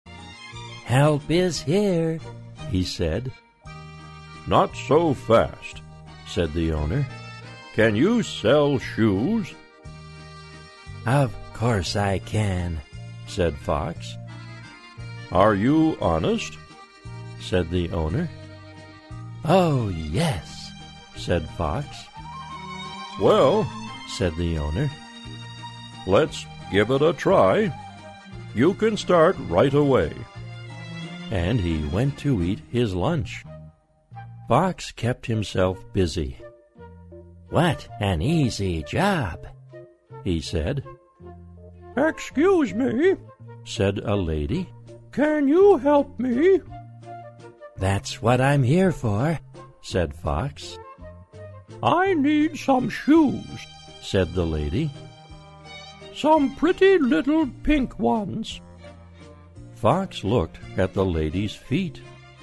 在线英语听力室小狐外传 第76期:诚实的听力文件下载,《小狐外传》是双语有声读物下面的子栏目，非常适合英语学习爱好者进行细心品读。故事内容讲述了一个小男生在学校、家庭里的各种角色转换以及生活中的趣事。